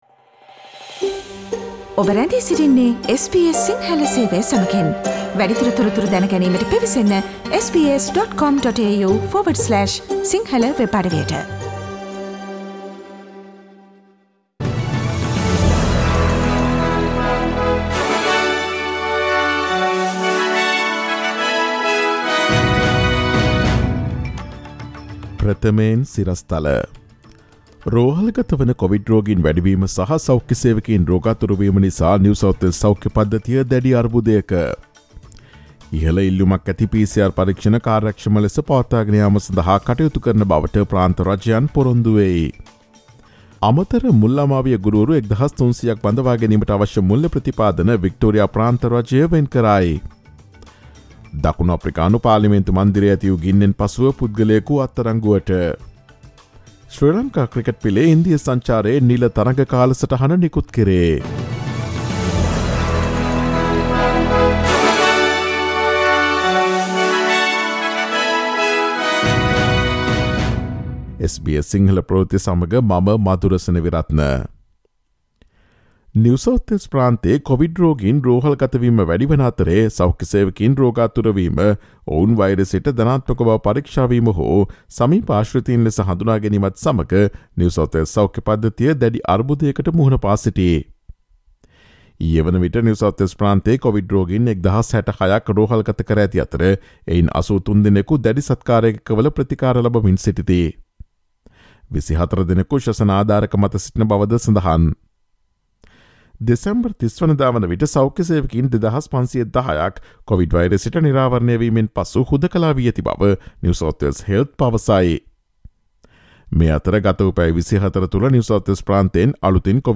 ජනවාරි 03 දා SBS සිංහල ප්‍රවෘත්ති: රෝහල්ගත කොවිඩ් රෝගීන් වැඩිවීම නිසා NSW සෞඛ්‍ය පද්ධතිය අර්බුදයක
ඔස්ට්‍රේලියාවේ නවතම පුවත් මෙන්ම විදෙස් පුවත් සහ ක්‍රීඩා පුවත් රැගත් SBS සිංහල සේවයේ 2022 ජනවාරි 03 වන දා සඳුදා වැඩසටහනේ ප්‍රවෘත්ති ප්‍රකාශයට සවන් දීමට ඉහත ඡායාරූපය මත ඇති speaker සලකුණ මත click කරන්න.